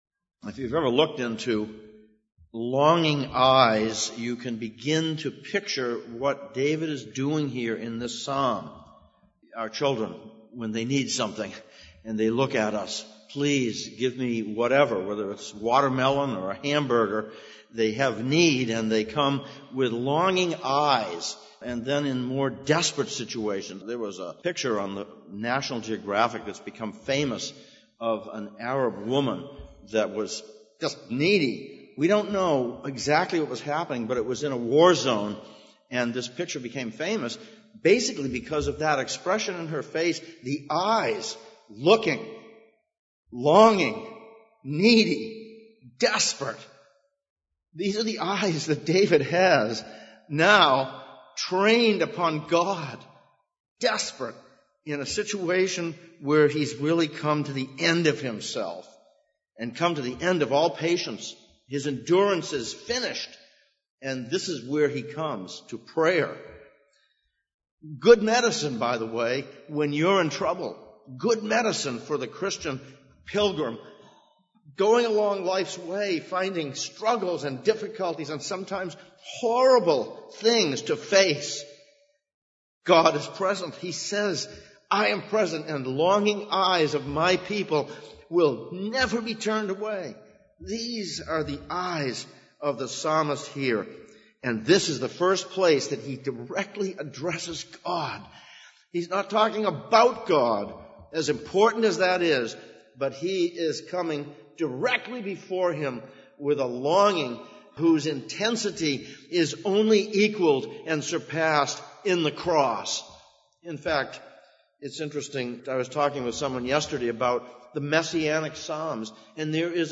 Psalms of Ascents Passage: Psalm 123:1-4, Hebrews 12:1-11 Service Type: Sunday Morning « 2.